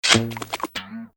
resources/phase_5/audio/sfx/jump.mp3 at 161c0a1d24e1aef6a2a50f78fb6eef3a2e71aa94
jump.mp3